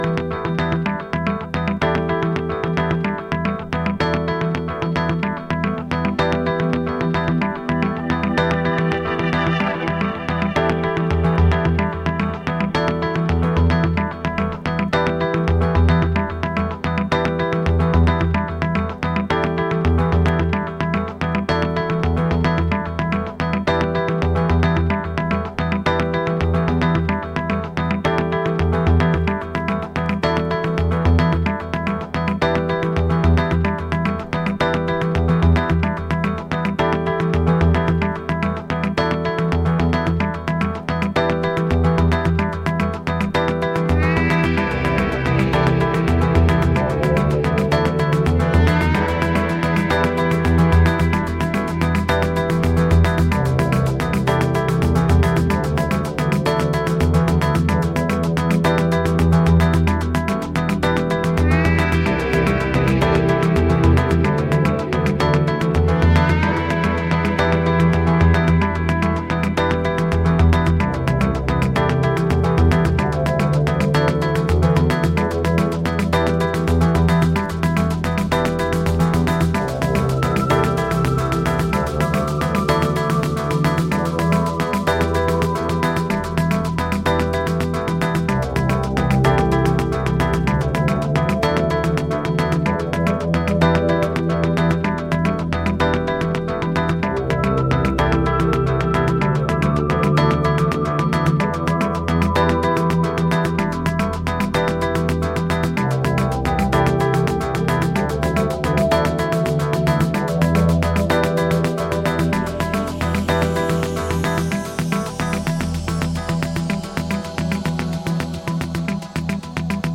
Electronix Ambient